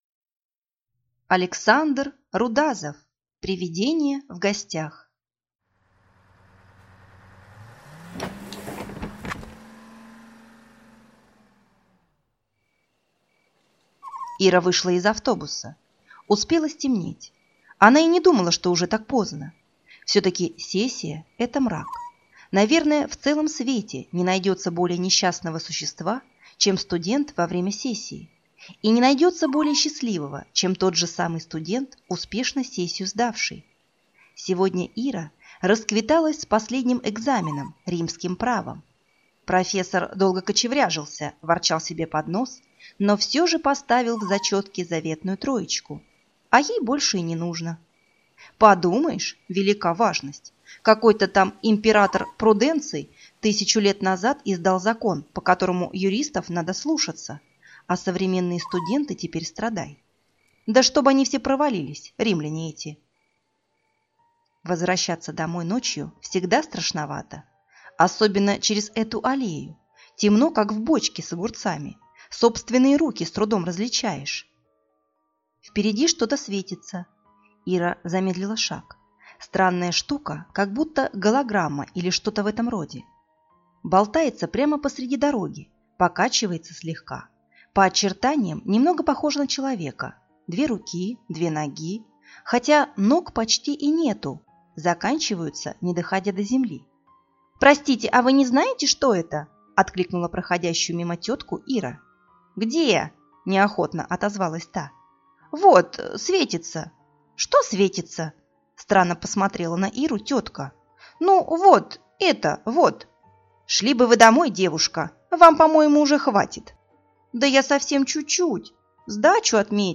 Аудиокнига Привидение в гостях | Библиотека аудиокниг